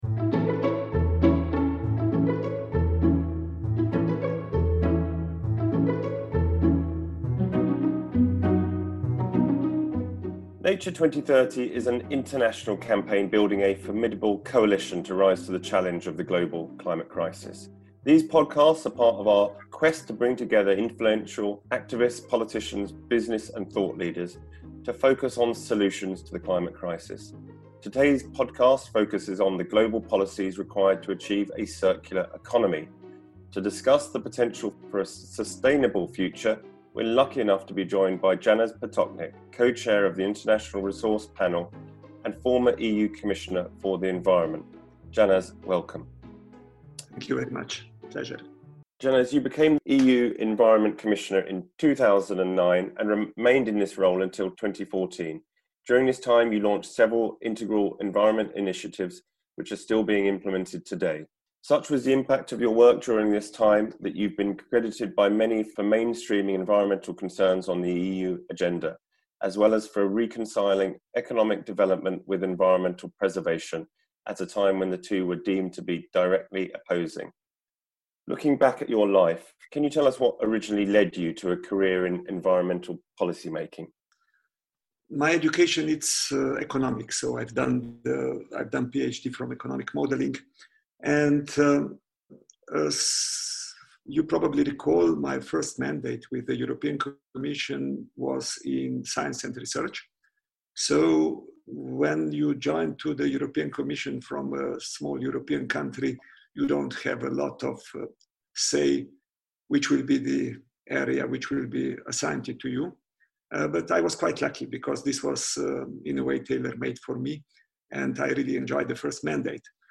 is joined by Co-Chair of the UN International Resource Panel Janez Potočnik